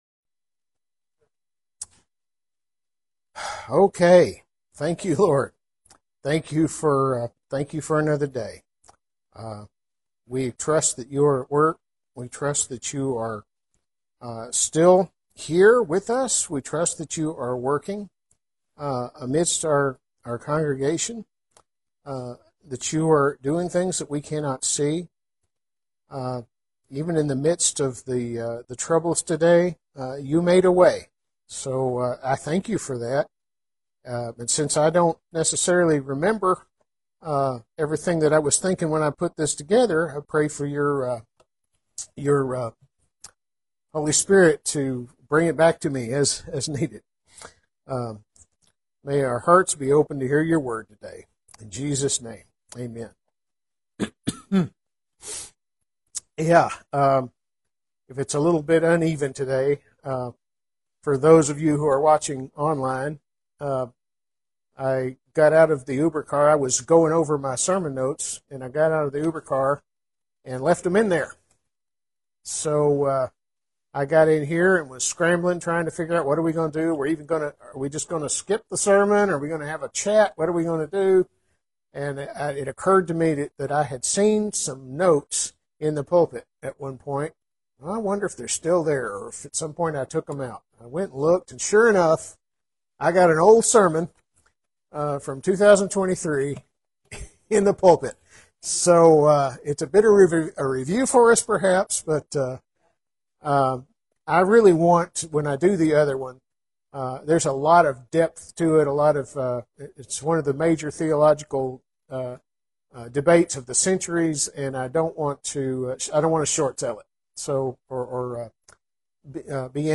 The pastor left his notes in an Uber and found these old notes in the pulpit, so some might say that God changed the message, but one thing is certain, He knew it would happen that way, so here you go.